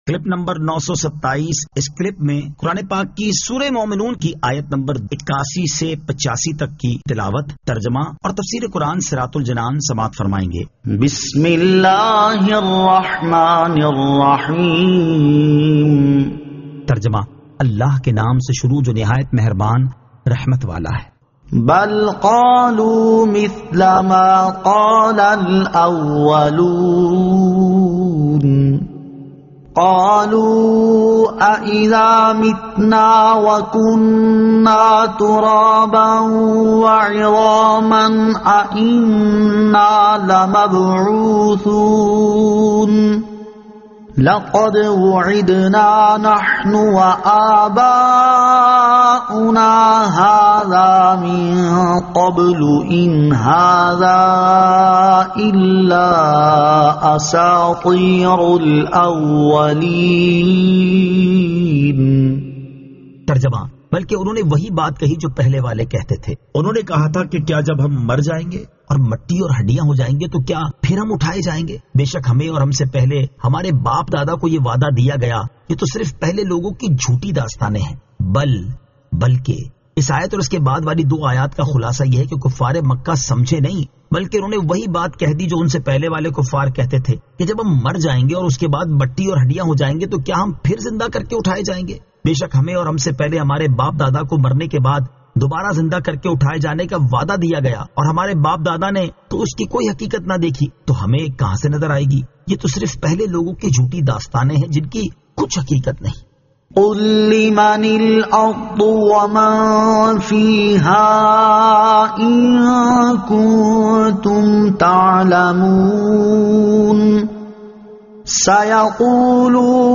Surah Al-Mu'minun 81 To 85 Tilawat , Tarjama , Tafseer